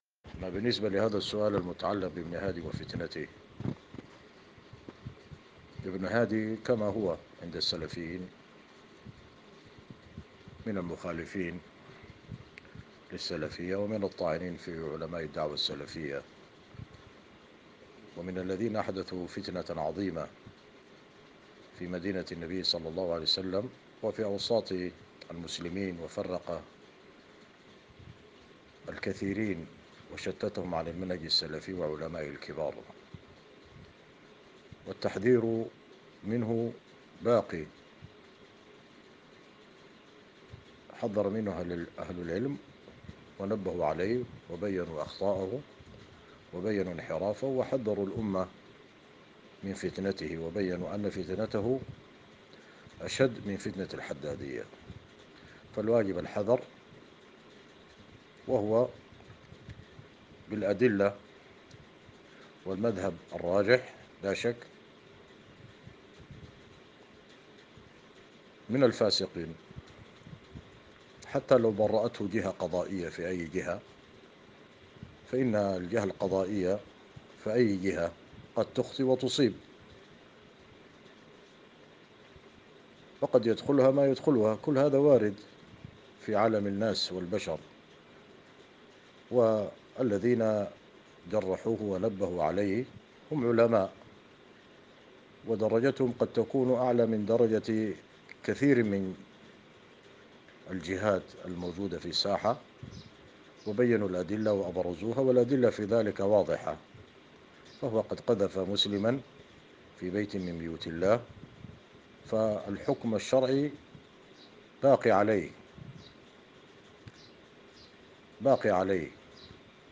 [جواب صوتي]